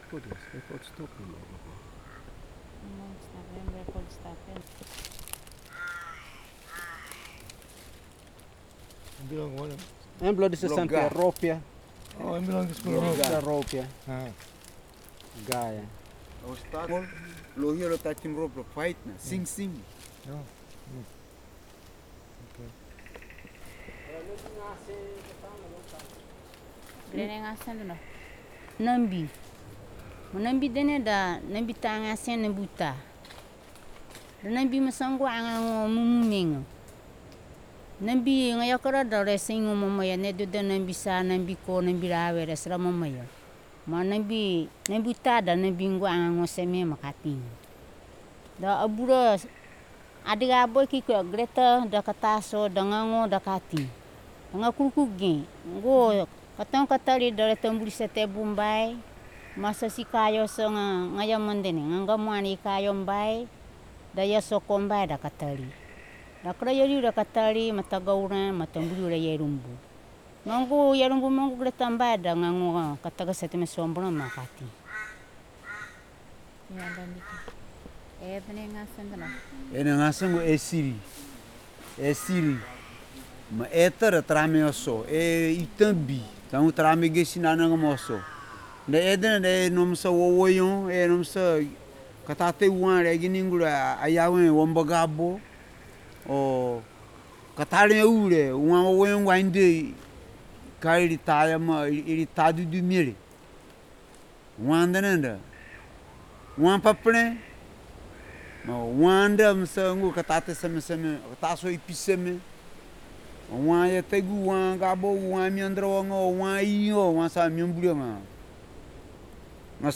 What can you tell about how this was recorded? dc.description.regionKamiali (Lababia village), Morobe Province, Papua New Guinea dc.formatrecorded at 44.1khz/16 bit on a solid state Zoom H2 recorder